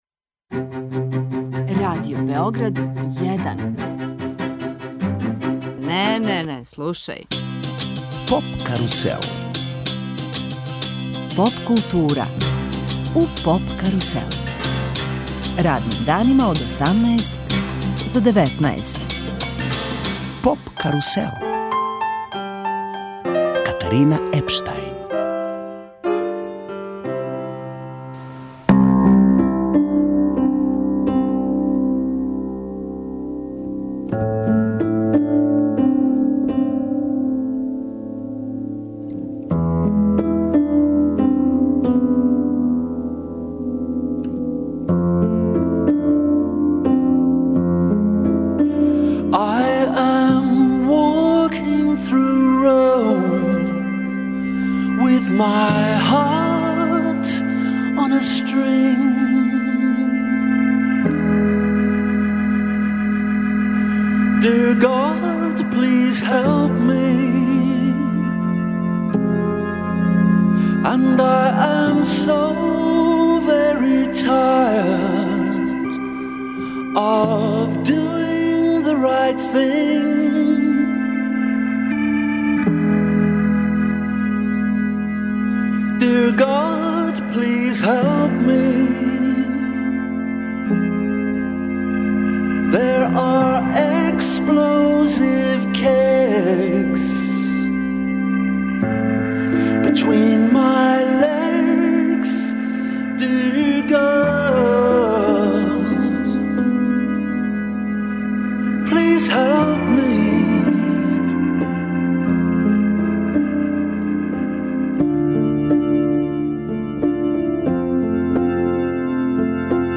У студију су и бендови ИНТРУДЕР, ВИРВЕЛ и нови састав, на српској сцени ТИ.